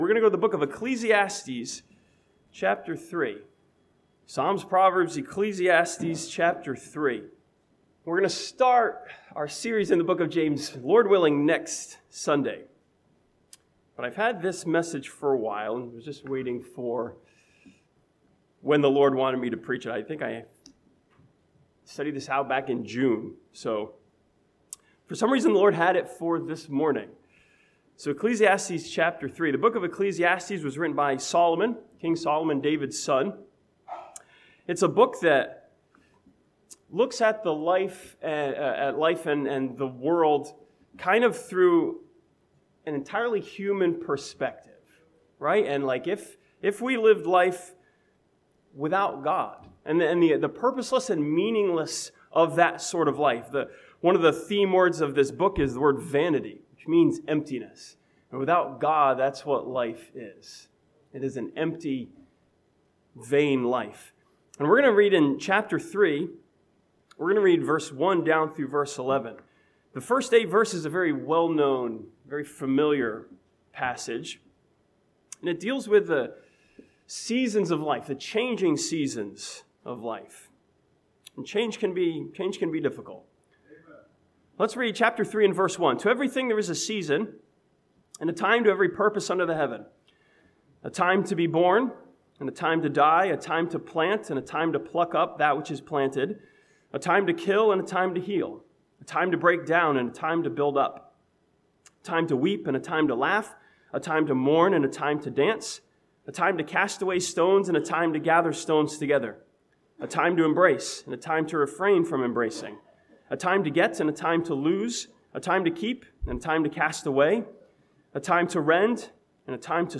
This sermon from Ecclesiastes chapter 3 studies the changing seasons of life and how to deal with the frustrations of change.